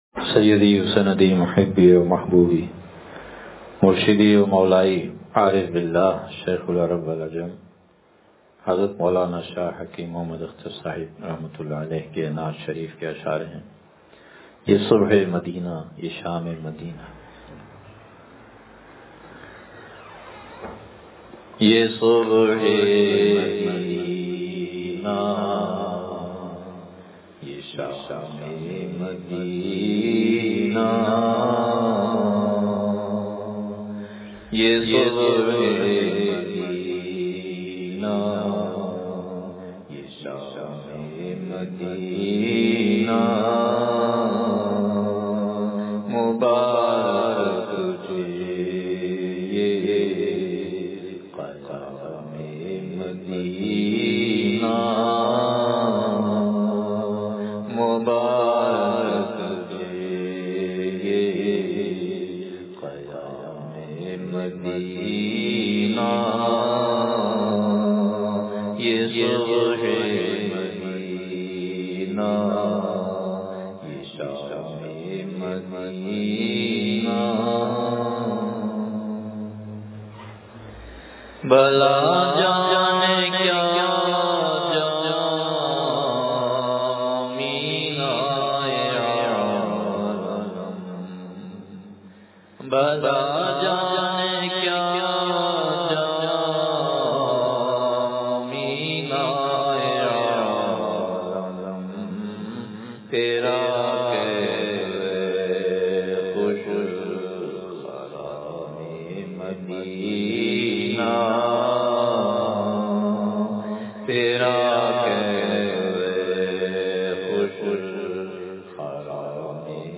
یہ صبح مدینہ یہ شامِ مدینہ – اصلاحی بیان